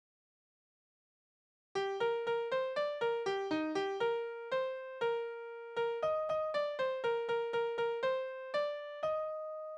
Kindertänze: Wir fahren nach Jerusalem
Tonart: B-Dur (notiert), Es-Dur (effektiv)
Taktart: 4/4
Tonumfang: Oktave
Anmerkung: - impliziter Taktwechsel von Takt 3 auf Takt 4 - notierte Tonart ist B-Dur/g-moll, aber das Stück liegt und klingt in Es-Dur